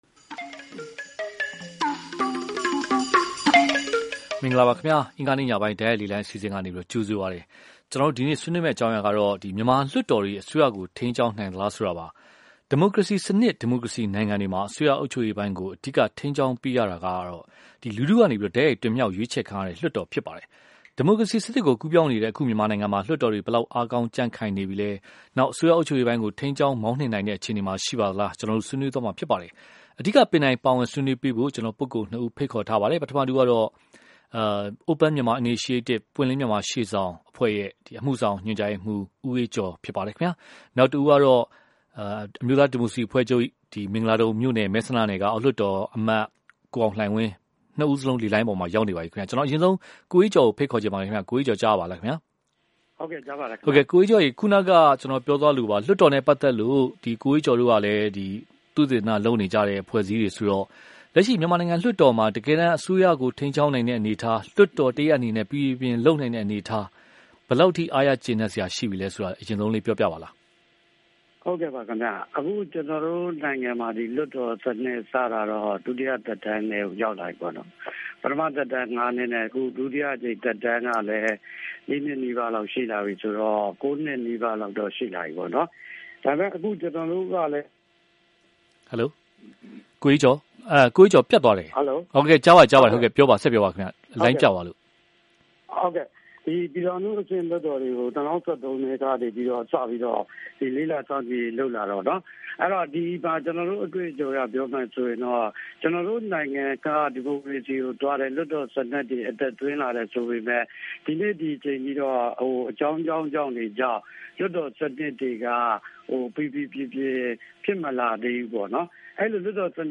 မြန်မာလွှတ်တော်တွေ အစိုးရကိုထိန်းကျောင်းနိုင်သလား (တိုက်ရိုက်လေလှိုင်း)